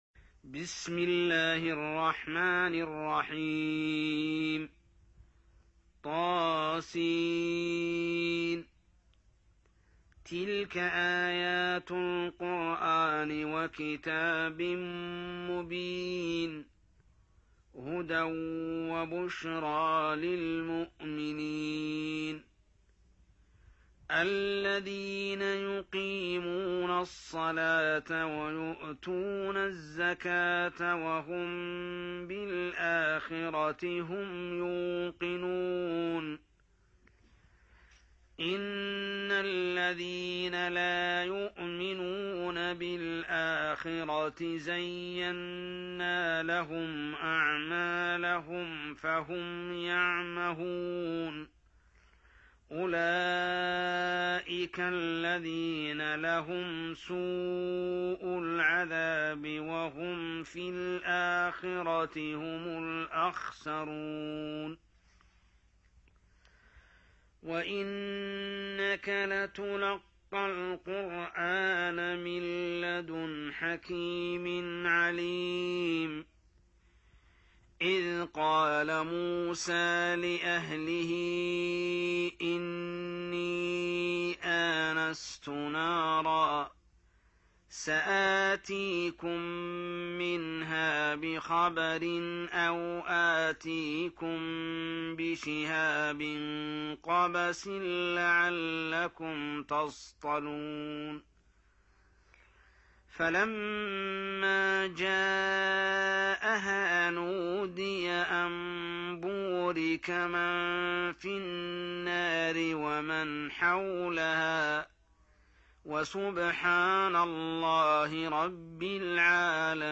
27. Surah An-Naml سورة النّمل Audio Quran Tarteel Recitation
Surah Sequence تتابع السورة Download Surah حمّل السورة Reciting Murattalah Audio for 27.